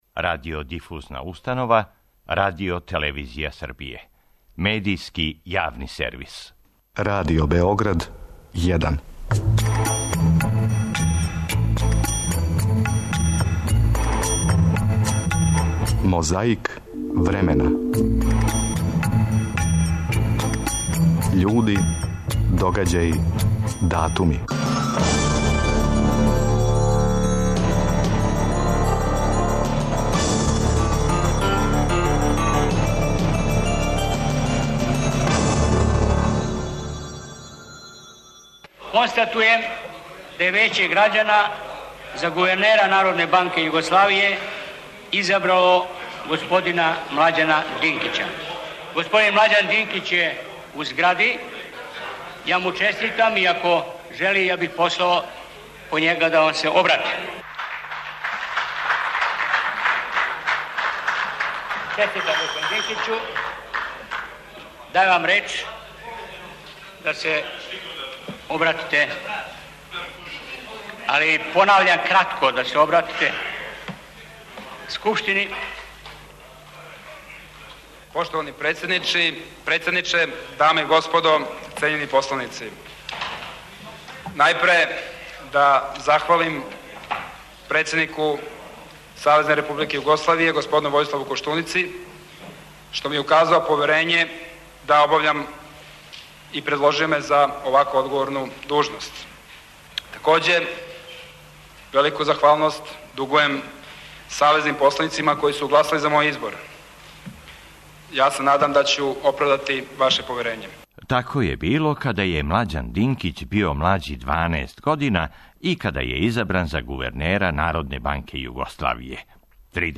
Свечана академија поводом 800 година манастира Студеница одржана је 29. октобра 1986.
Чућете шта је тим поводом истакао тадашњи председник Скупштине Србије, друг Бранислав Иконић.